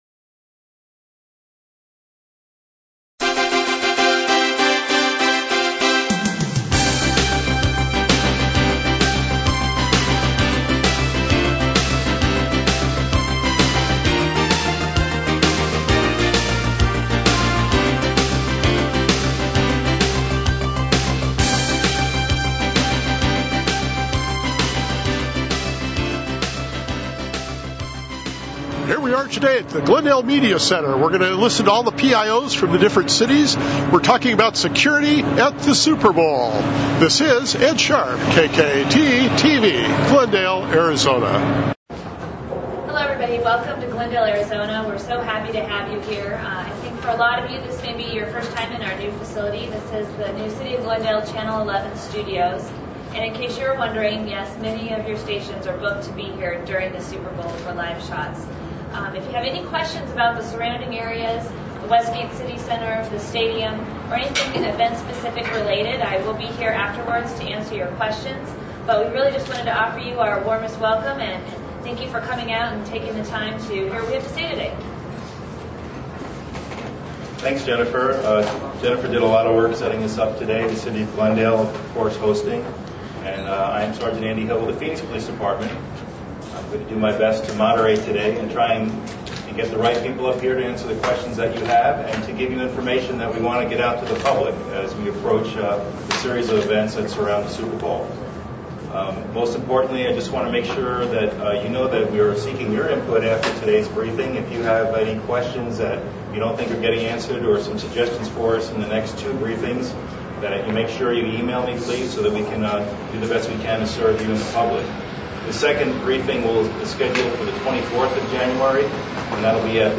Regional Media Workgroup for the Super Bowl Holds Briefing for the Media.
superbowl median briefing 1audio.mp3